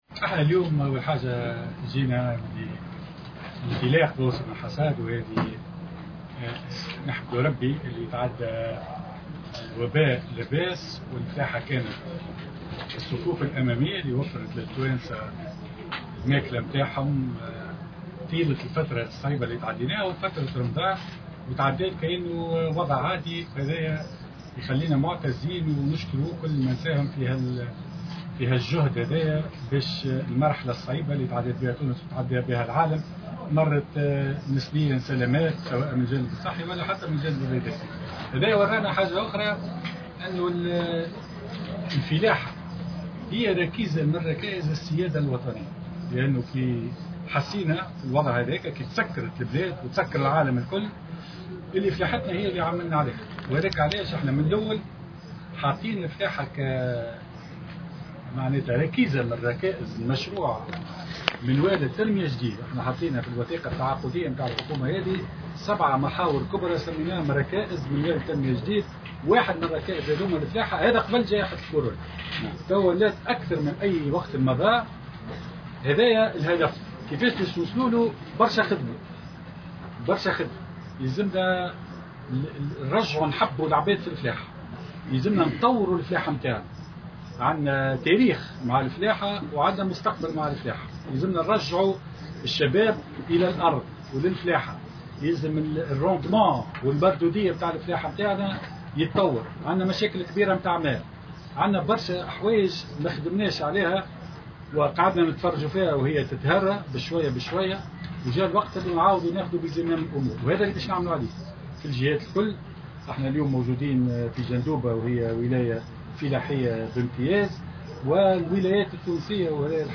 وقال الفخفاخ، في تصريح لمراسل الجوهرة أف أم، لدى إشرافه اليوم السبت، في جندوبة على انطلاق موسم الحصاد، إن الوقت قد حان لرد الاعتبار للقطاع الفلاحي وتثمينه في المنوال التنموي الجديد للبلاد التونسية، باعتباره ركيزة أساسية للسيادة الوطنية، وهو ما تضمنته الوثيقة التعاقدية لحكومته.